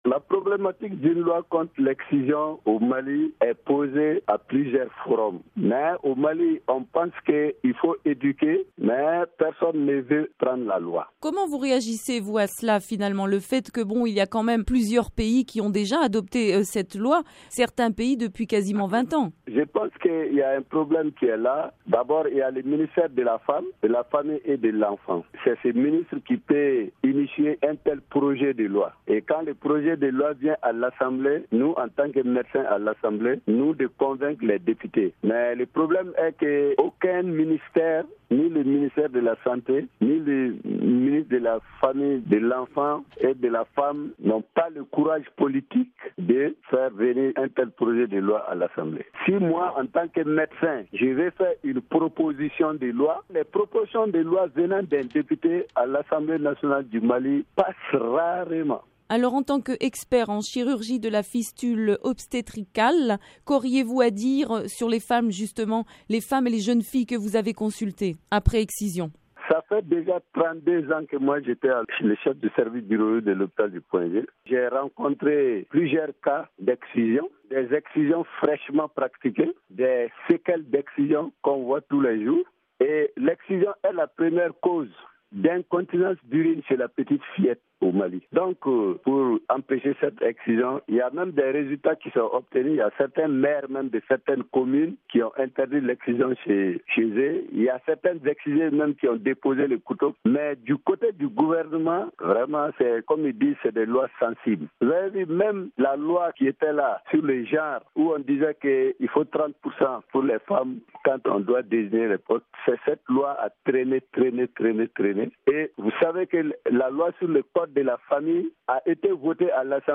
MGF 2017- Dr Kalilou Ouattara, député malien